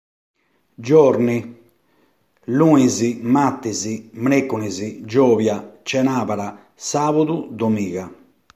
ascolta come si dicono i giorno, i mesi e le stagioni nel paese di Senis